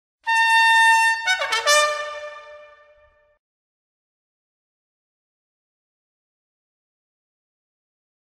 Toques e Fanfarra das Tropas Pára-quedistas